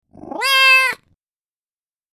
gato.mp3